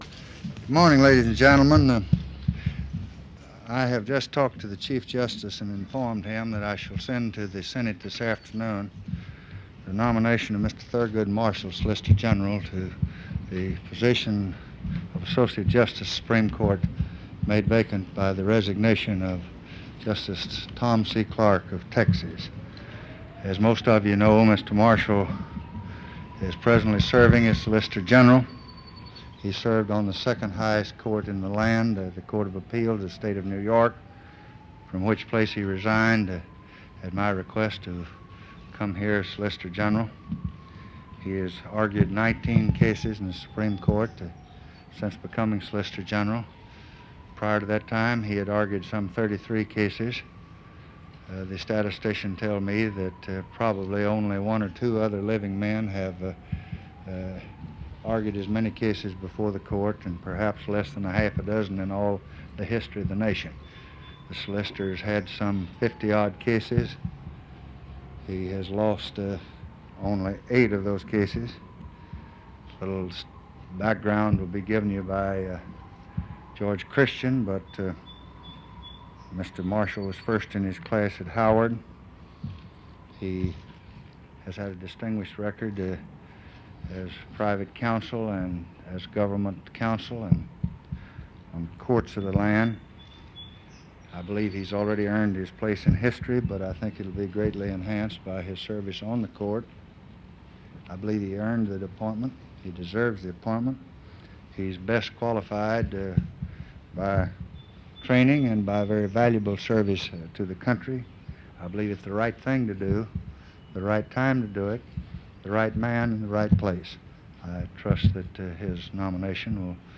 1965년 린든 B. 존슨 대통령은 서굿 마셜을 미국의 송무차관으로 임명하였고, 1967년 6월 13일 톰 C. 클라크 판사의 퇴직에 따라 그를 미국 대법원에 지명하면서 "옳은 일이요, 그것을 할 옳은 시간이요, 옳은 남자이자 옳은 장소이다"라고 말했다.[8][12] 같은 날 존슨 대통령은 백악관 장미 정원에서 마셜의 대법관 지명을 발표하며, "이 임명을 받을 자격이 있으며… 이것이 올바른 일이고, 올바른 시기이며, 올바른 사람이고, 올바른 장소라고 믿습니다."라고 선언했다.
1967년 6월 13일, 마셜의 대법관 지명을 발표하는 존슨 대통령의 연설